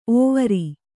♪ ōvari